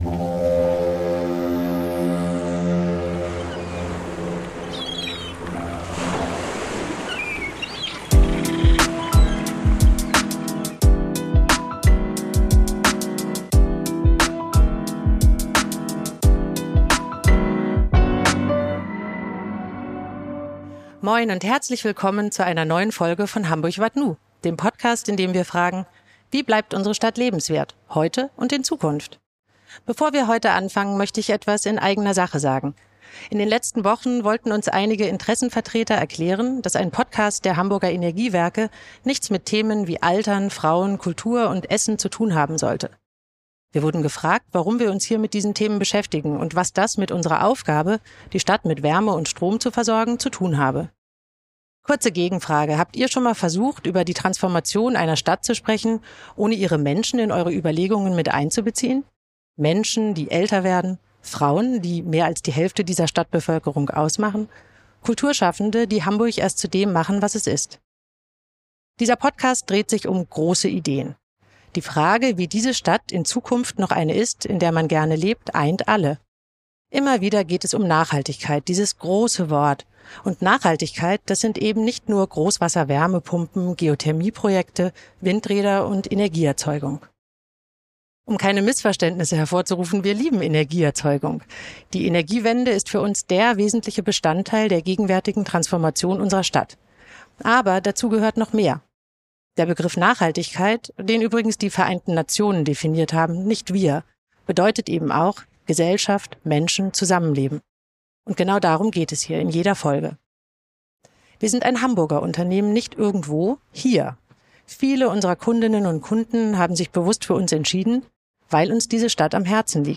Ein Gespräch über Brücken statt Inseln, über Verantwortung von Zivilgesellschaft, Politik und Wirtschaft – und darüber, was Hamburg braucht, um in allen 105 Vierteln gut zusammenzuleben. Aufgenommen im Energiebunker Wilhelmsburg – mit Blick auf eine Stadt, in der Zusammenhalt genauso entscheidend ist wie eine sichere und nachhaltige Energieversorgung.